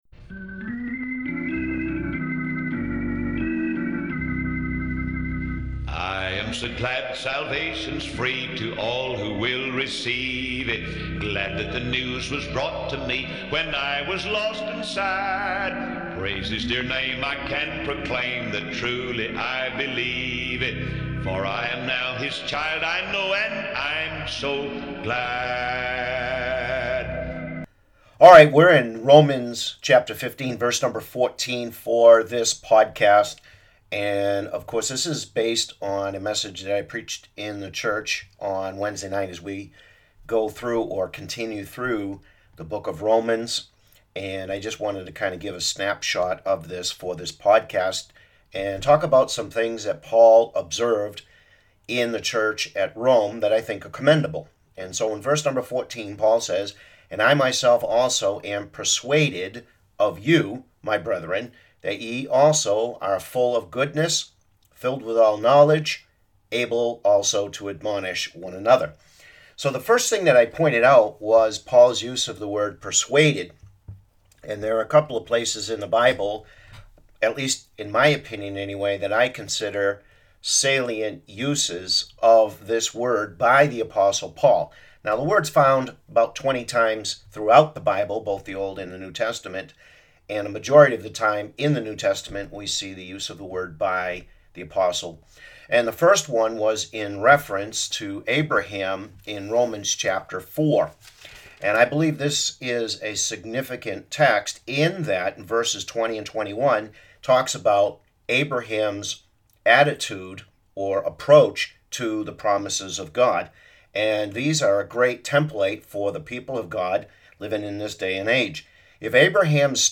Just some gleanings from a message I preached Wednesday as we continue our series through the book of Romans